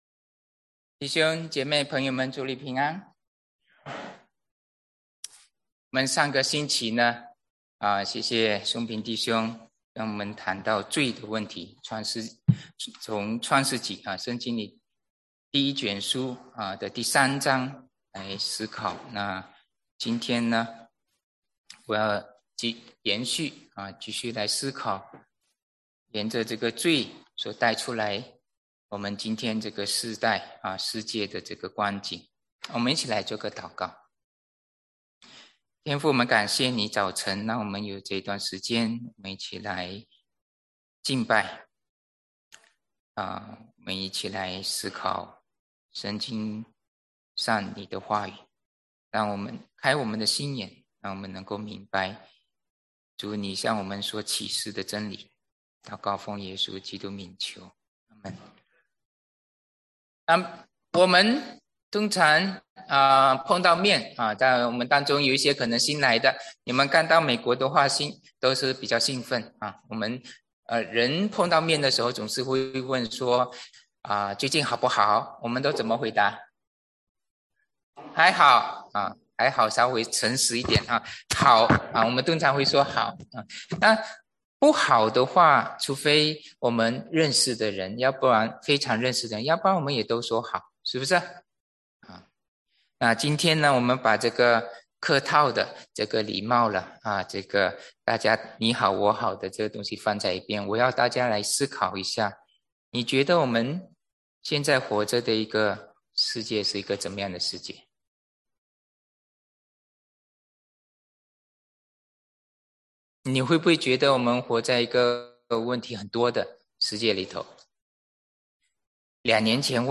Mandarin Sermons – Page 20 – 安城华人基督教会